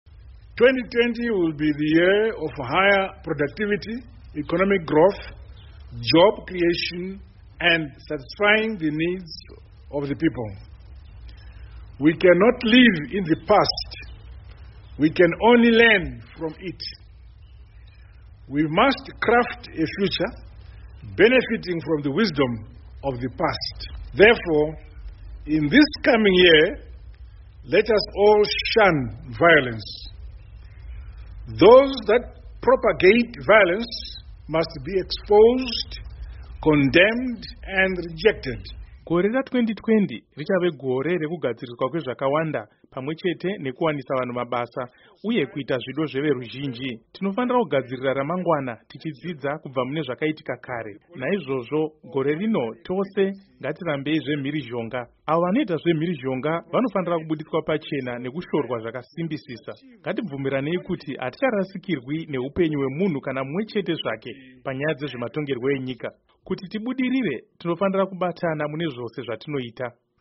Mashoko aVaMnangagwa